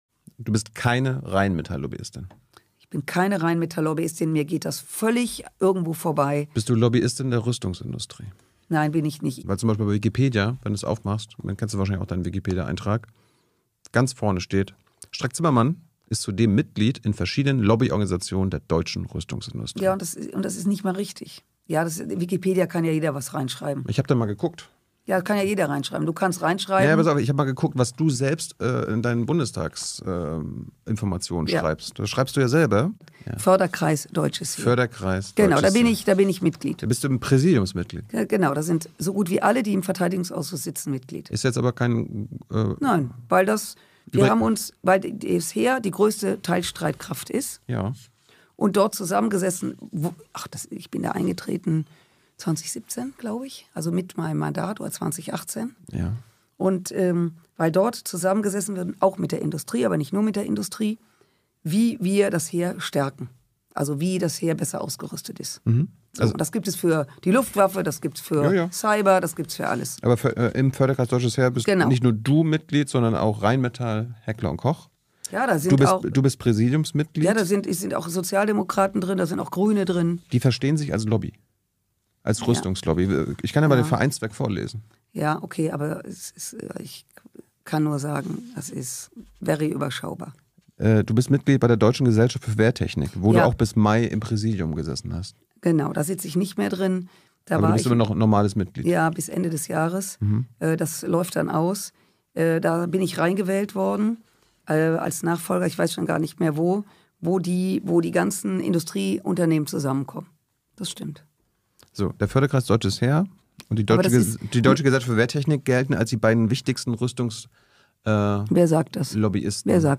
Hier die wichtigsten Aussagen vom Interview von Tilo Jung ("Jung & Naiv") mit Strack-Zimmermann bezüglich ihrer Funktion als Lobbyistin zusammengefasst.
Sie will cool wirken, um das Straucheln zu verstecken.